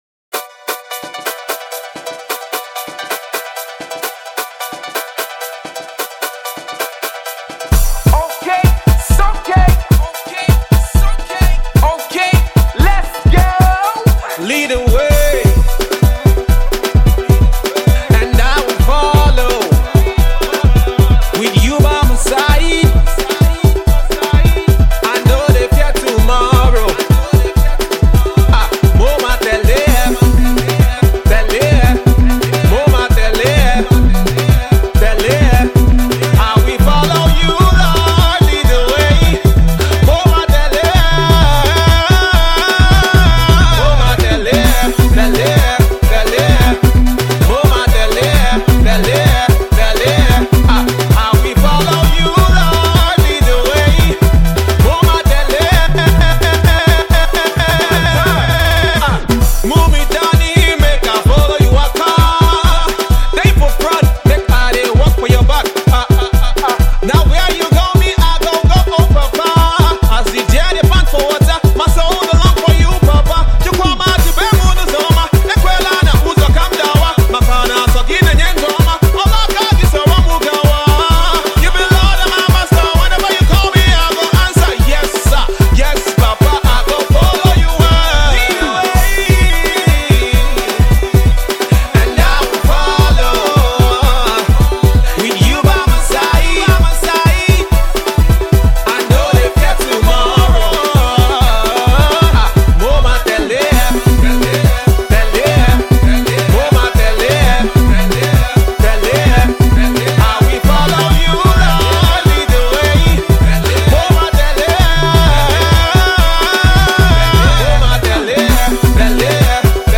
exciting new  Afro-Dance Gospel number